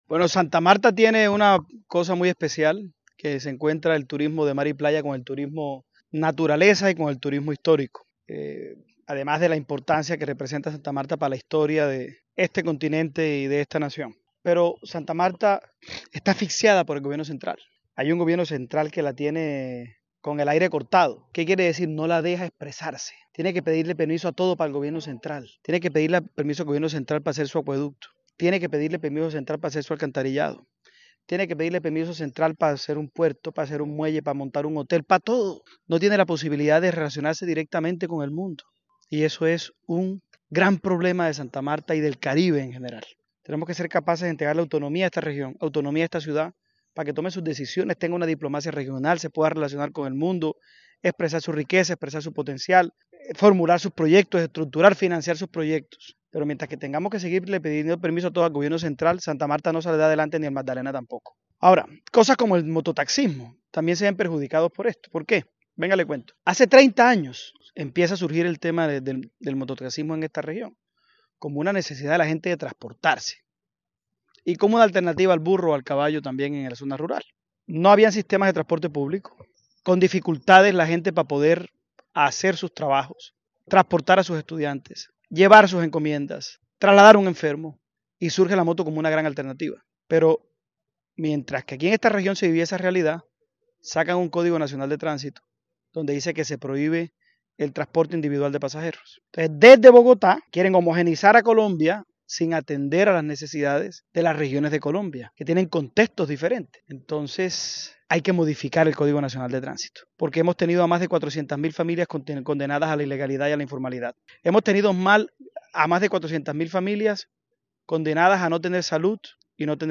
HECTOR OLIMPO, PRECANDIDATO PRESIDENCIAL